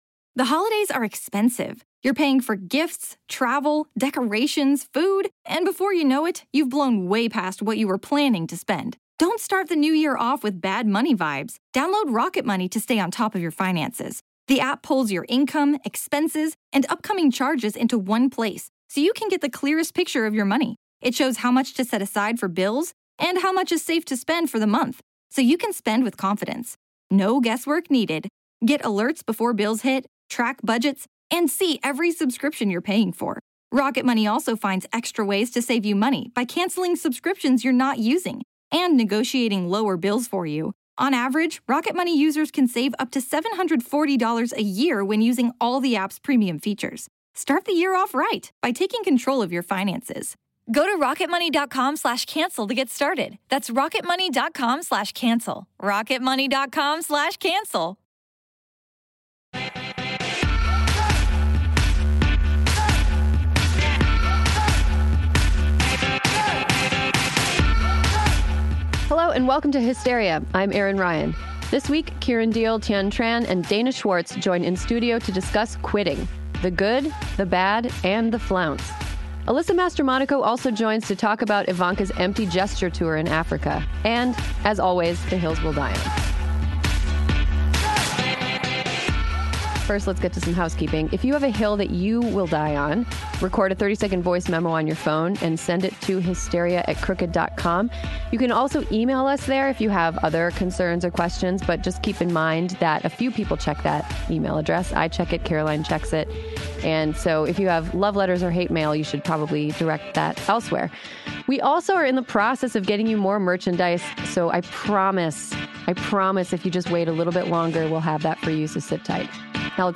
Plus, Alyssa Mastromonaco calls in to break down why Ivanka Trump’s photo op tour in Africa is a new hypocritical high, even for her.